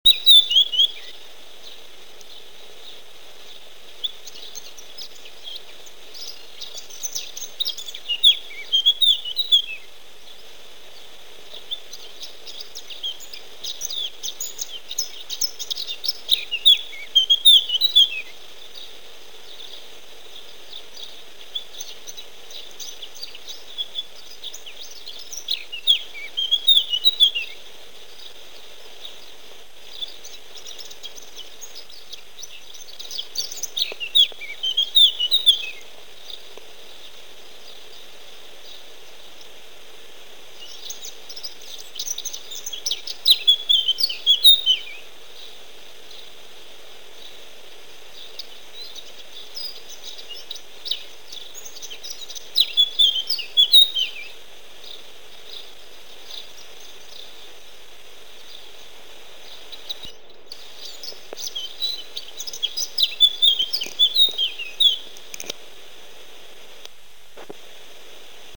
Toutinegra-de-cabeça-preta
Sylvia melanocephala
Macho
Habitat: Na vegetação arbustiva
Comportamento: O canto é um chilrear e matraquear áspero.